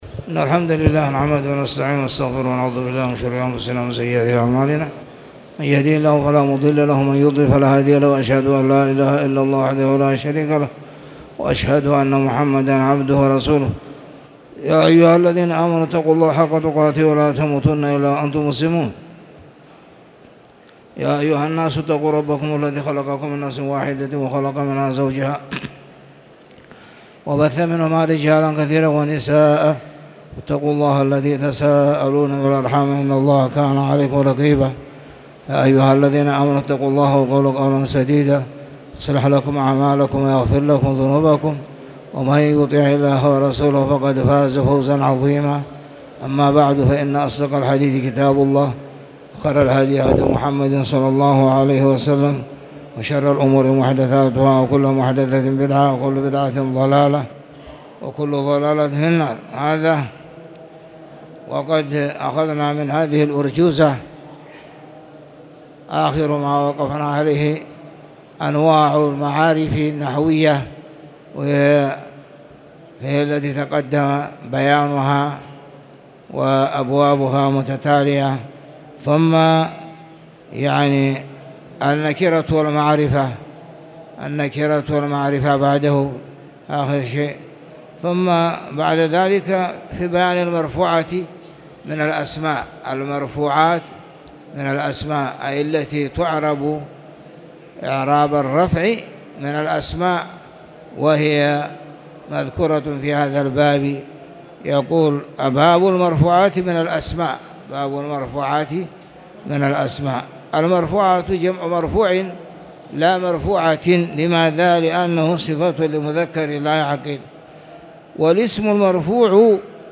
تاريخ النشر ٢٦ ذو الحجة ١٤٣٨ هـ المكان: المسجد الحرام الشيخ